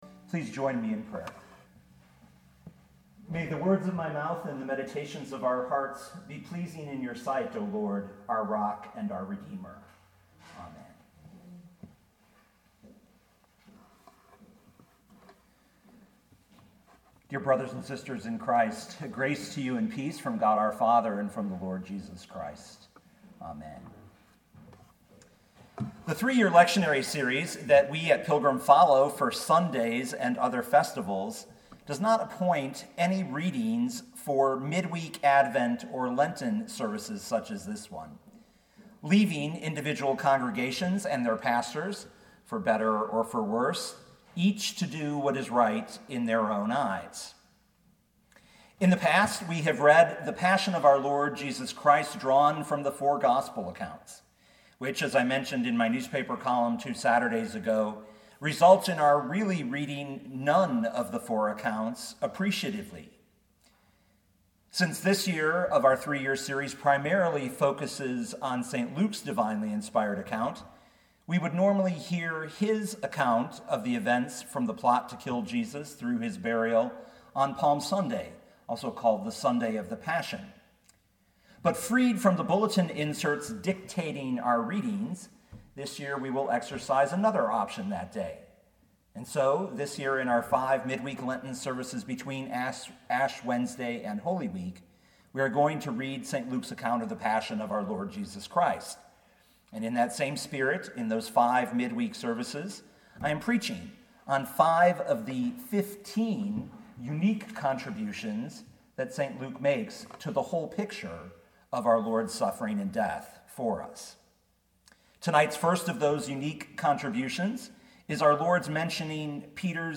2019 Luke 22:31-32 Listen to the sermon with the player below, or, download the audio.